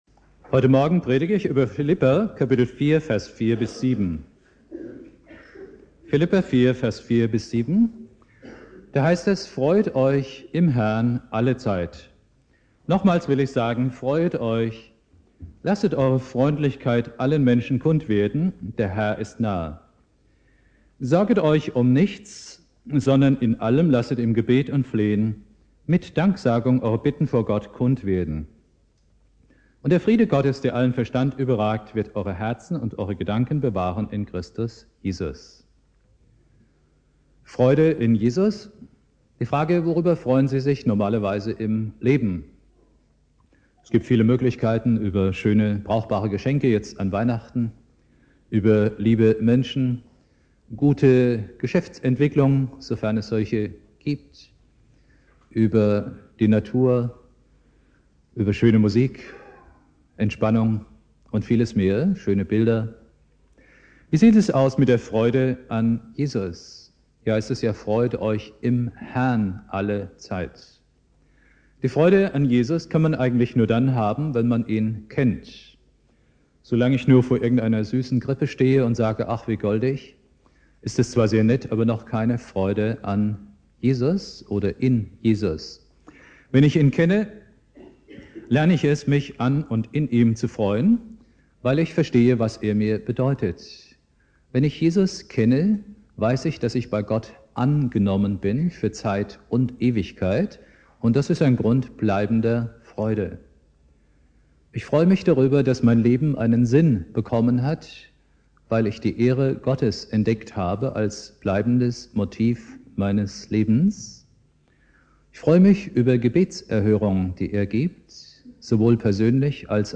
Predigt
4.Advent Prediger